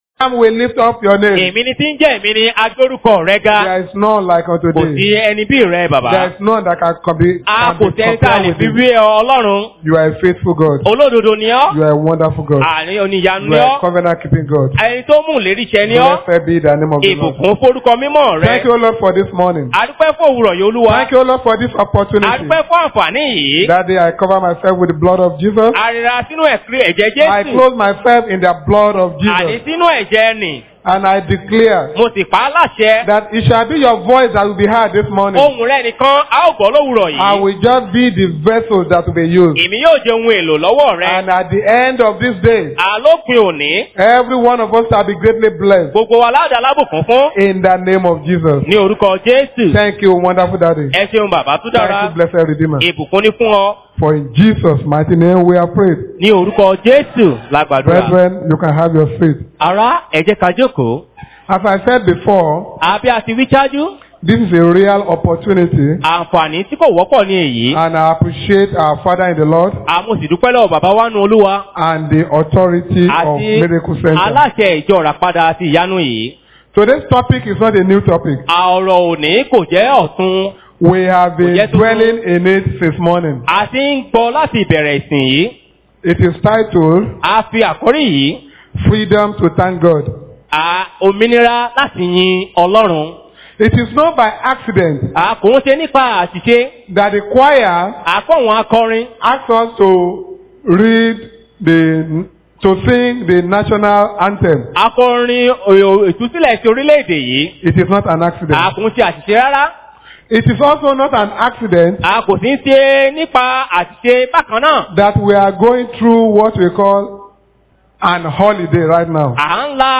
Posted in Thanksgiving Service